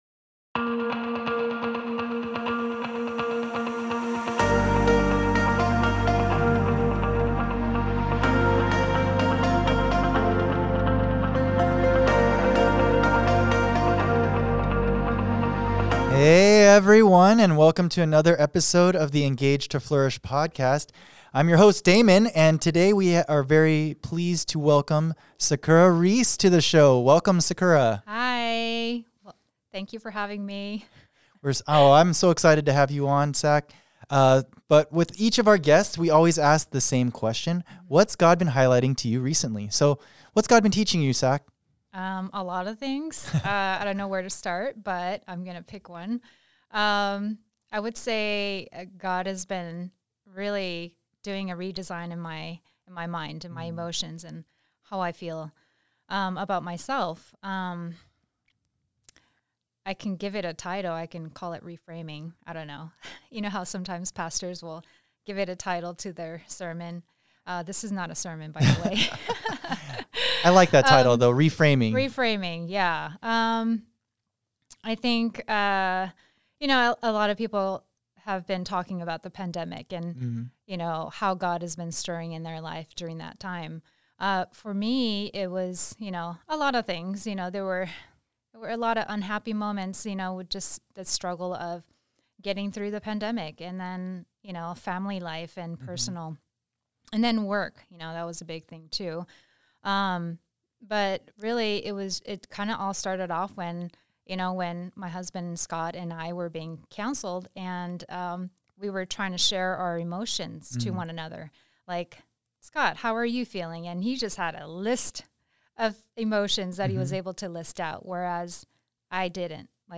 Join in on this very relatable conversation!